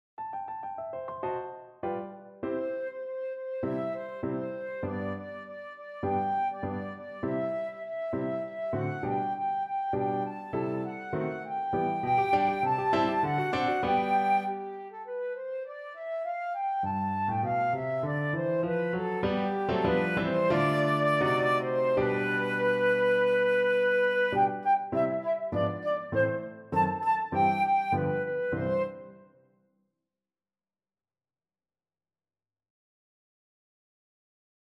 2/4 (View more 2/4 Music)
Quick and Light
Classical (View more Classical Flute Music)